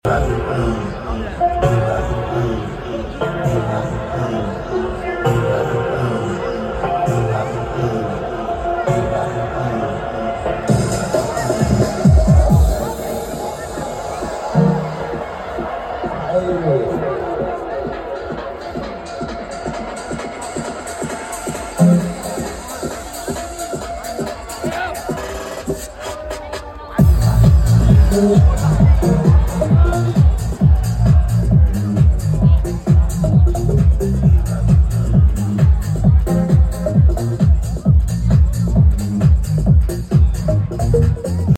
Proper moody crowd for the sound effects free download